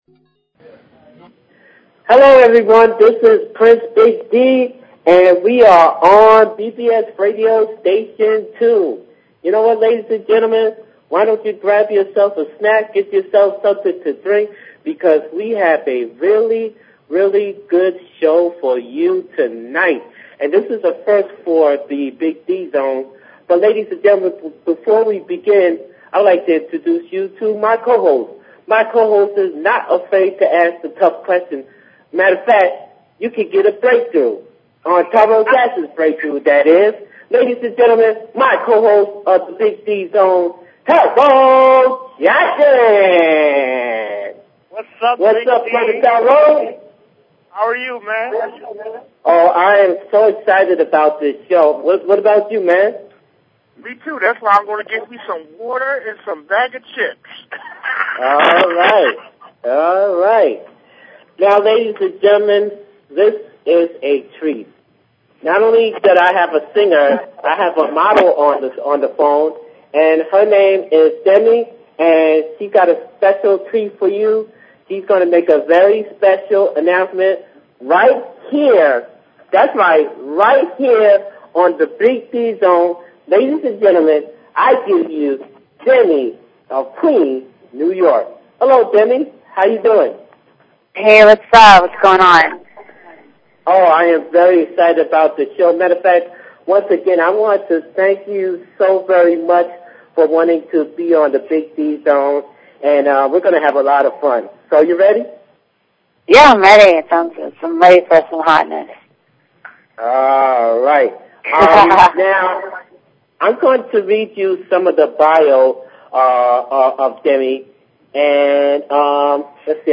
Talk Show Episode
we interviewed her and hade some more fun during the whole show!